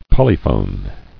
[pol·y·phone]